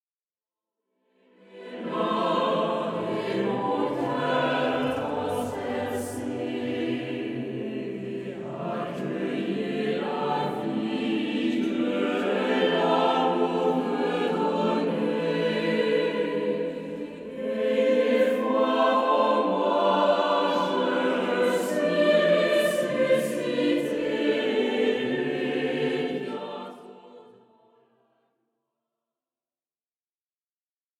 Harmonisations originales de chants d'assemblée